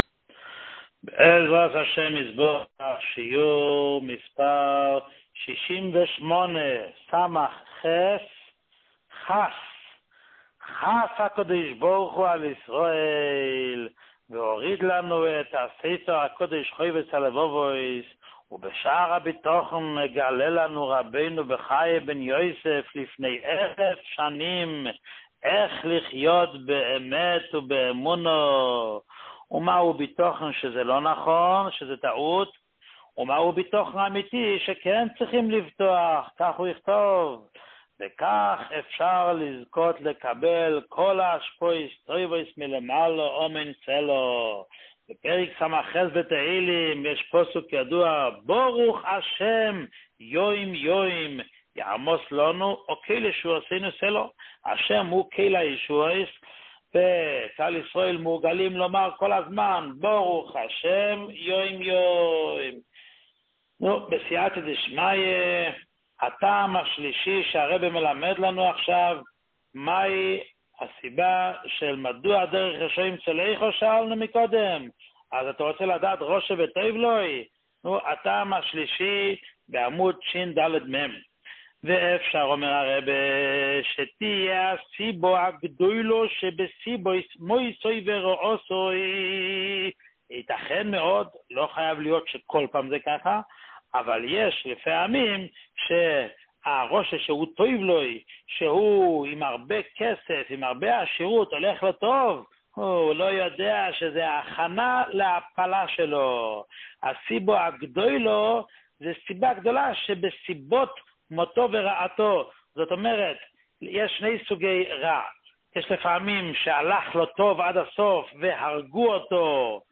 שיעור 68